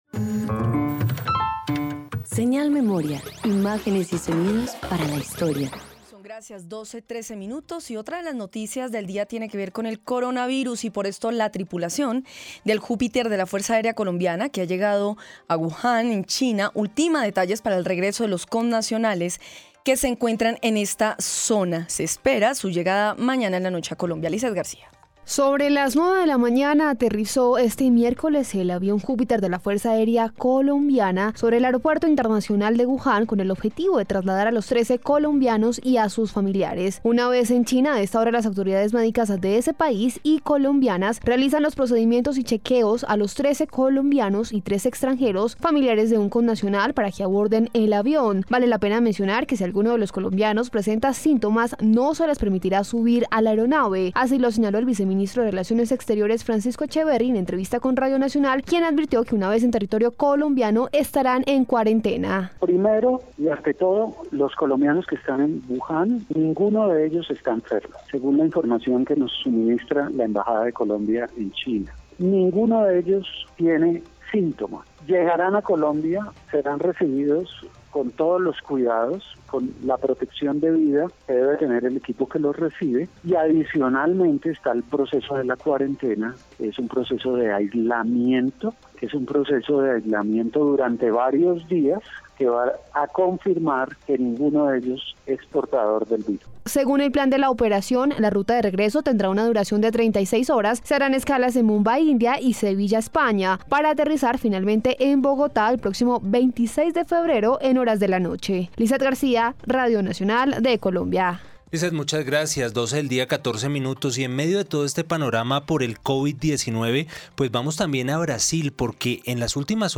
Alocución presidencial sobre el primer caso de Covid-19 en el país_0.mp3